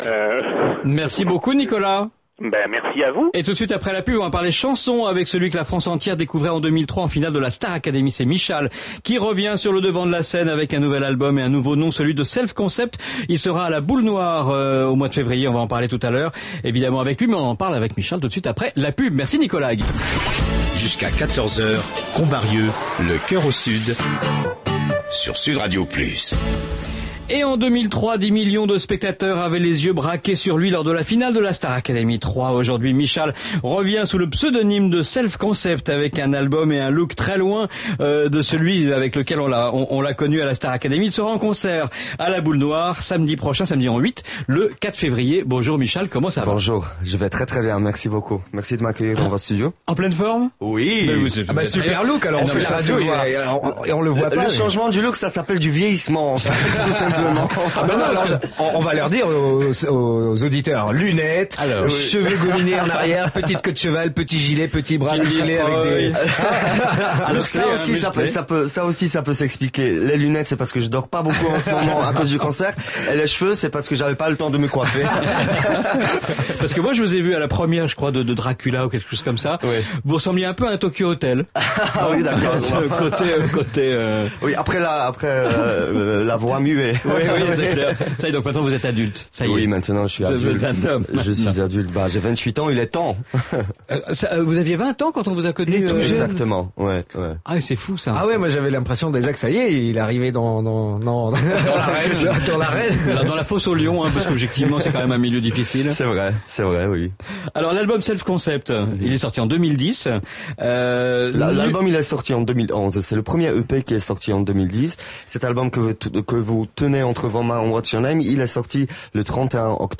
Michal a décroché une interview significative d'une vingtaine de minutes sur Sud Radio dans l'émission "Le coeur au Sud" ce Vendredi 27 janvier 2012.